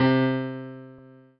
piano-ff-28.wav